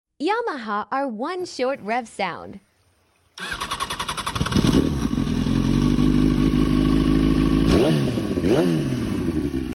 Yamaha r1 short rev sound sound effects free download